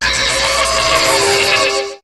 Cri de Câblifère dans Pokémon HOME.